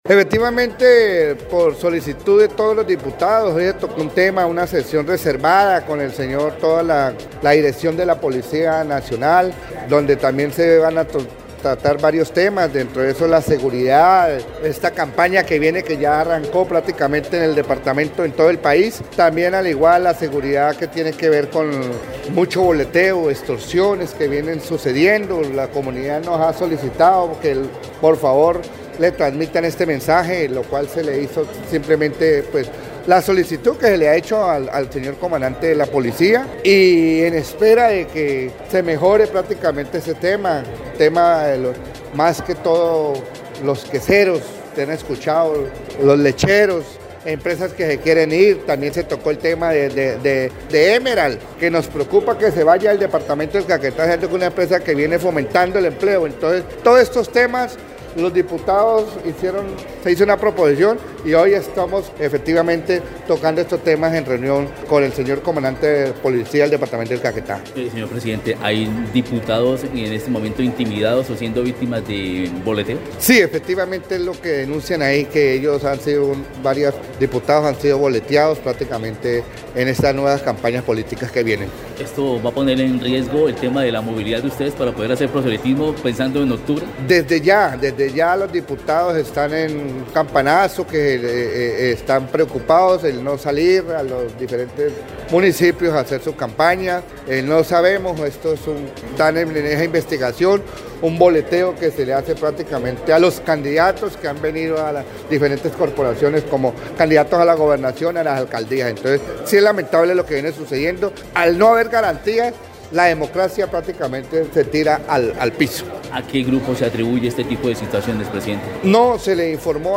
Luis Alfredo Silva Neira, actual presidente de la entidad, explicó que son varios los diputados afectados por estas acciones ilegales y exigieron de la policía mayores garantías a la hora de adelantar sus recorridos por el territorio caqueteño.
01_DIPUTADO_LUIS_SILVA_SEGURIDAD.mp3